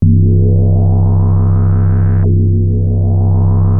JUP 8 C3 9.wav